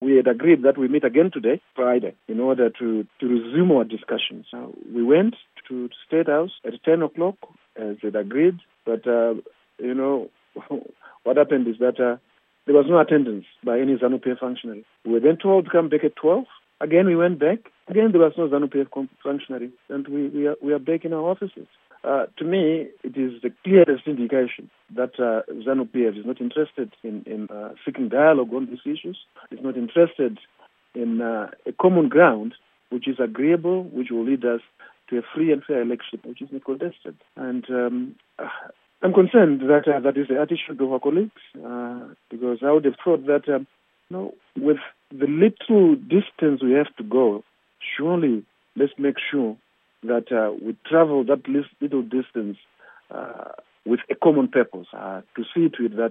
Interview With Eric Matinenga